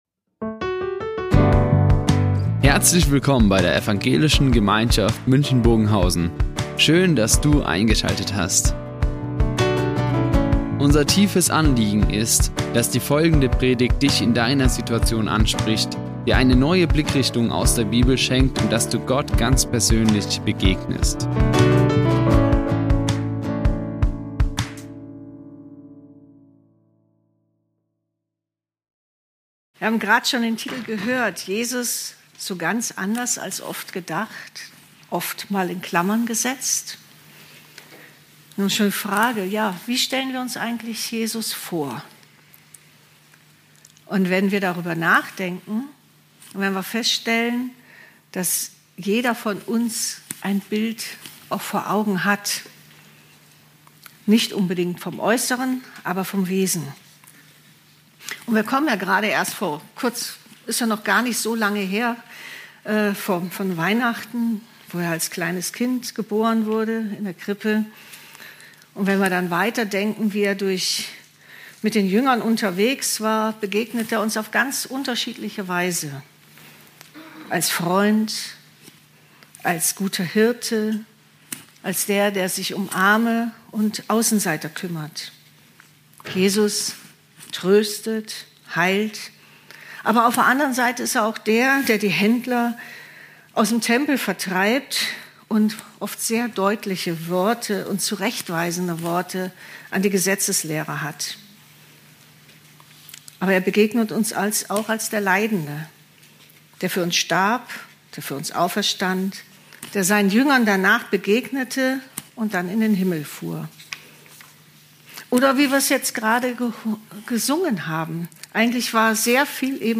Eine Predigt
Die Aufzeichnung erfolgte im Rahmen eines Livestreams.